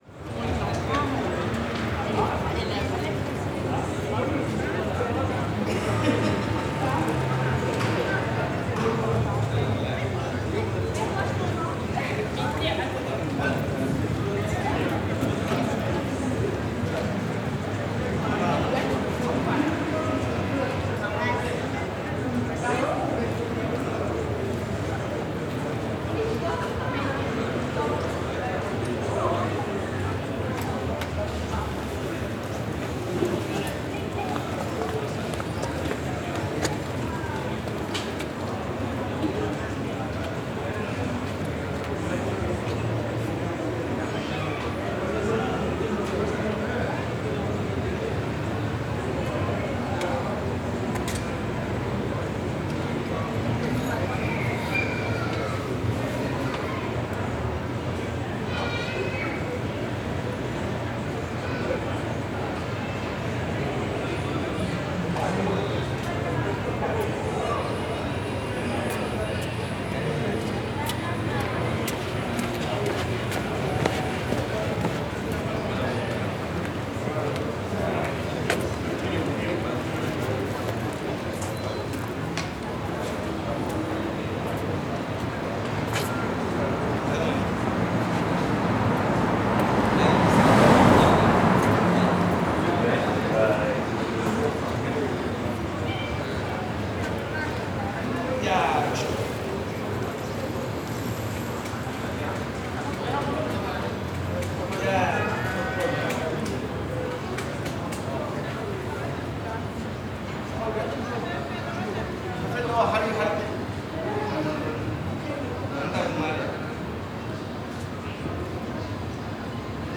Prise de son en extérieur face au centre Social Bisson au niveau du N°8.
fr CAPTATION SONORE
fr Scène de rue
fr Voiture
fr Animal
fr Vélo
fr Radio
fr Musique